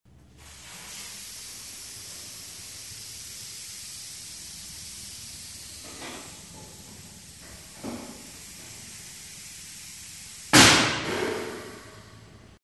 Шум накачивания шин в шиномонтажной мастерской